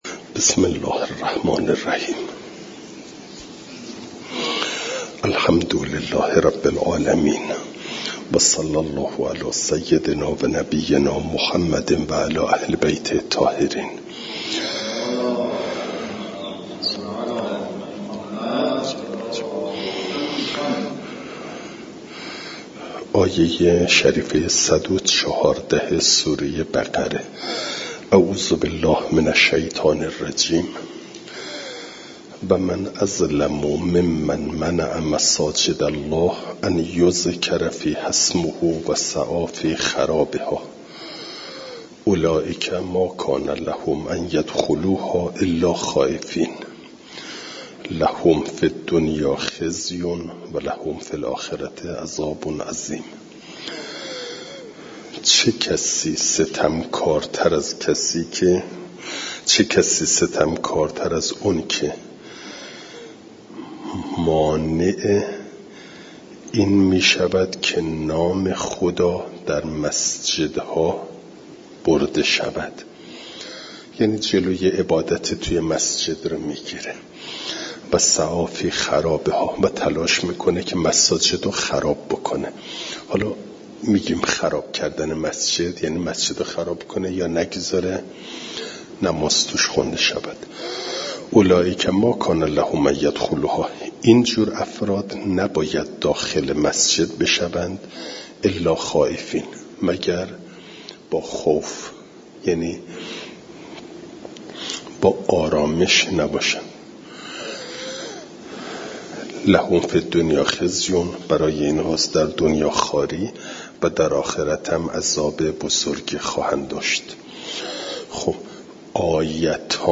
جلسه تفسیر قرآن